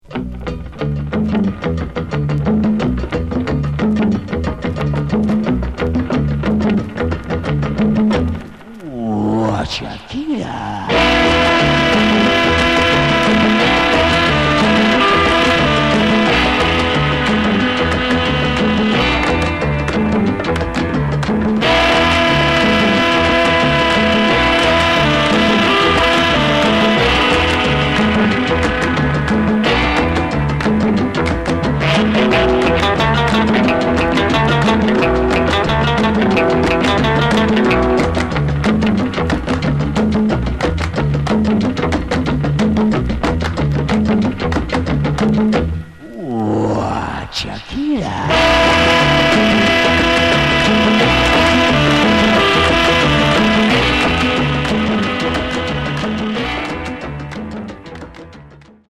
Genre: Instrumental Rock